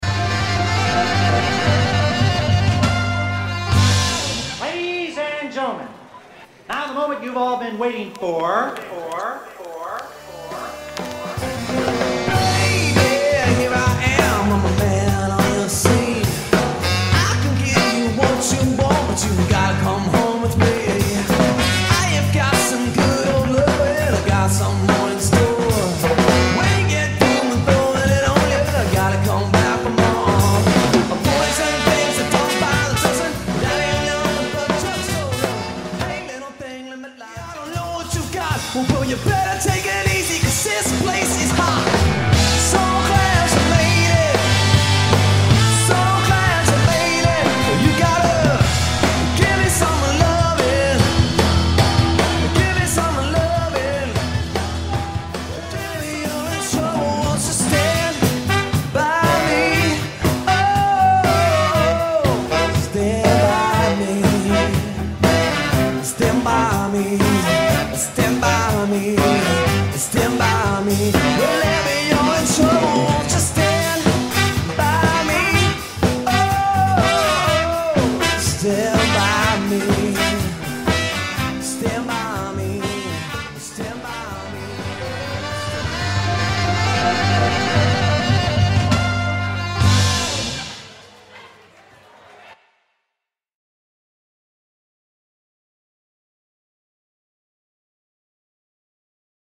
Vocals | Guitar | Bass | Drums | Sax | Trumpet | DJ